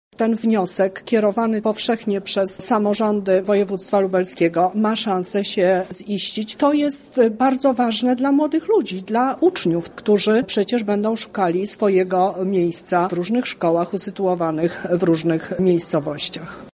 O wadze komunikacji między szkołami z różnych miejscowości mówi Teresa Misiuk, Lubelska Kurator Oświaty: